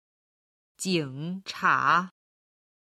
今日の振り返り！中国語音源
711-02-jingcha.mp3